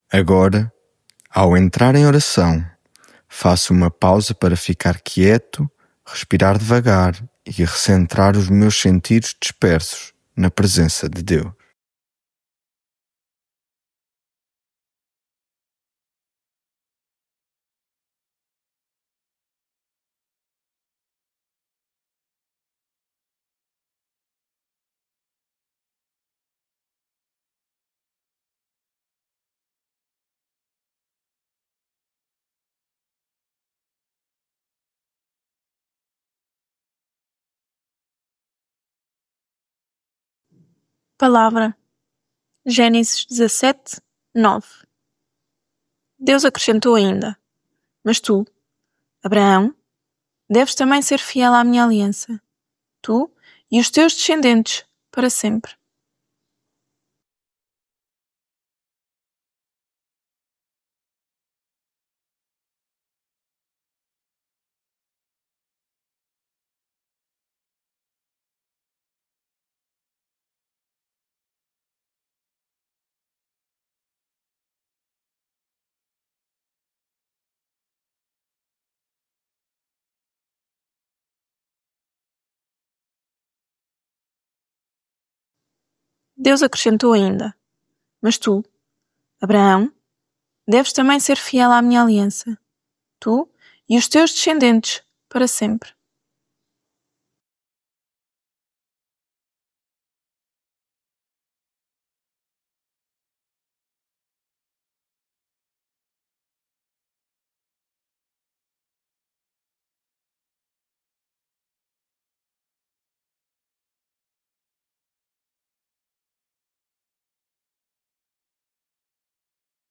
Devocional
lectio divina